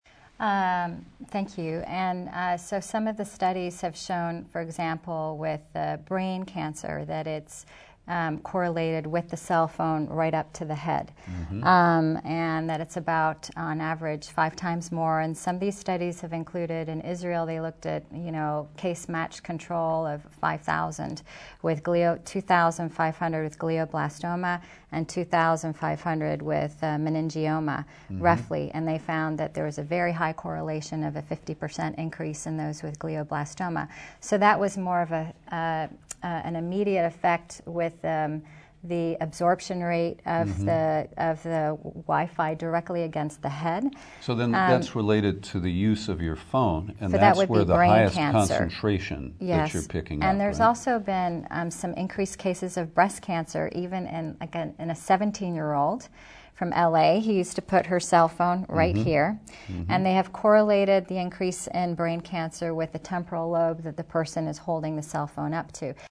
In-Depth Interviews: Panel Discussion on Health Risks and Other Issues with 5G Wireless